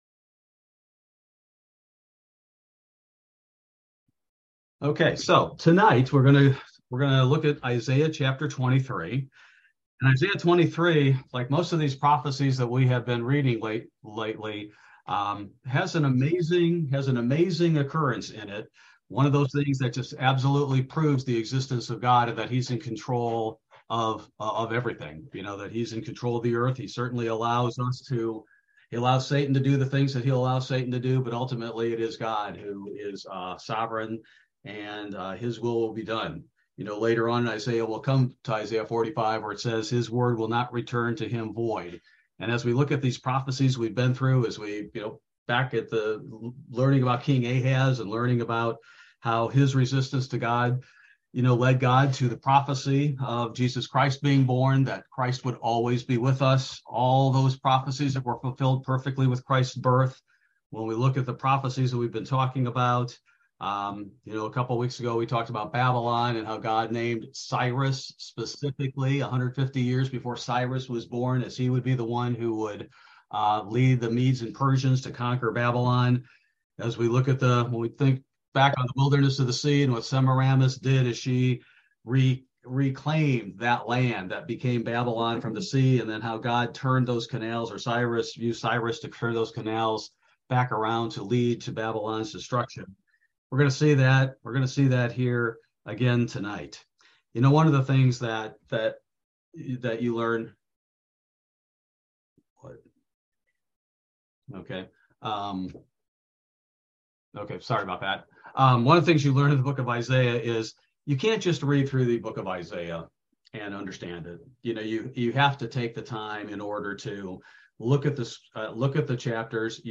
Bible Study: January 11, 2023